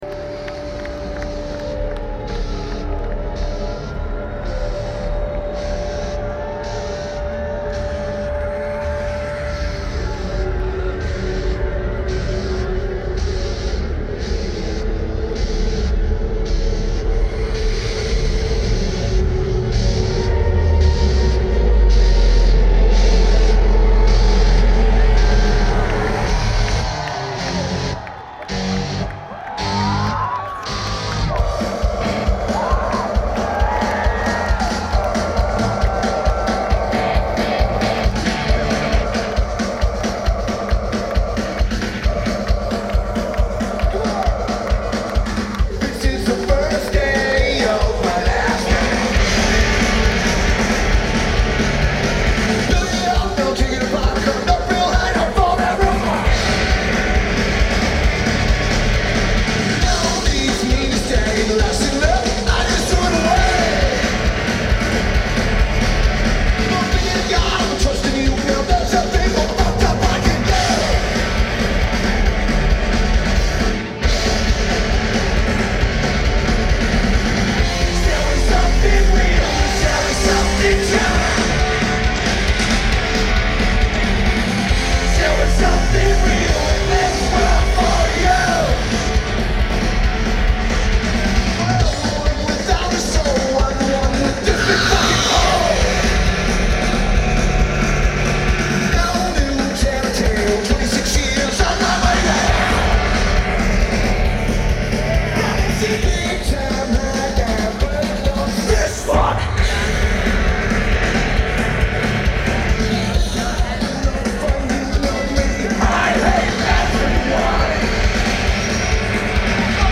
Lollapalooza in Grant Park